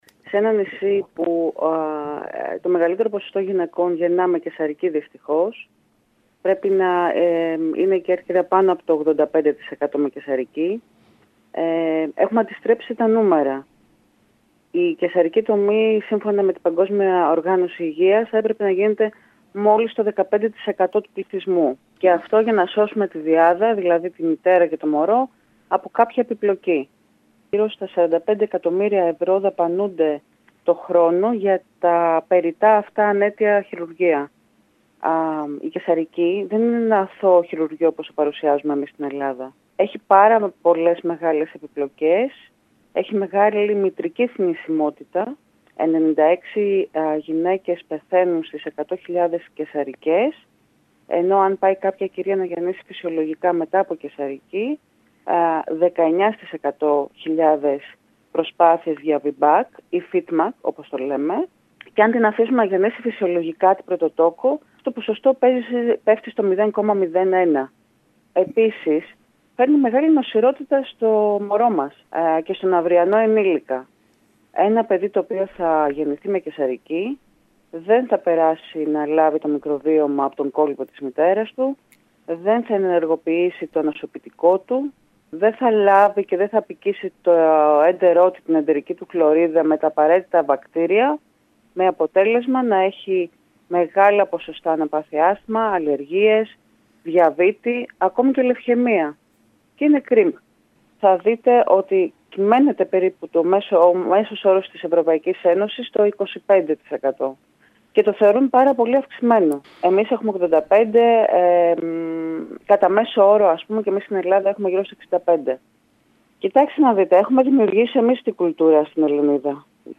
μίλησε στο σταθμό μας, τονίζοντας ότι η Κέρκυρα βρίσκεται στην κορυφή των καισαρικών οι οποίες καταγράφουν ποσοστό άνω του 85%, τη στιγμή που ο μέσος όρος στην Ελλάδα ανέρχεται στο 65% και το αποδεκτό ποσοστό διεθνώς δεν ξεπερνά το 25%. Σε μια καισαρική γέννα, όπως τονίζει ο πόνος δεν γίνεται αισθητός άμεσα αλλά τις επόμενες – πολλές – ημέρες, τα ποσοστά θνησιμότητας από επιπλοκές πολλαπλασιάζονται για τη μητέρα και το παιδί ενώ το βρέφος δεν αποκτά τα αντισώματα που το προσφέρει ο φυσιολογικός τοκετός με αποτέλεσμα να εκτίθεται σε σοβαρά προβλήματα κατά τη διάρκεια της ζωής του.